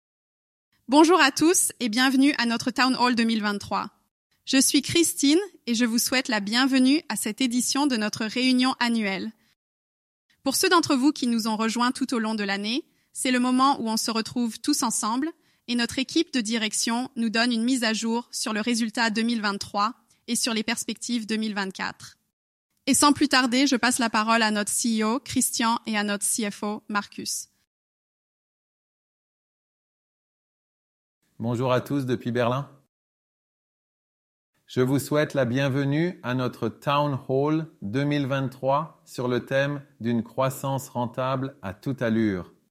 Le voice cloning recrée une voix humaine grâce à l’IA et des échantillons audio.
Exemple de Voice cloning :
Test_Voicecloning_FR.mp3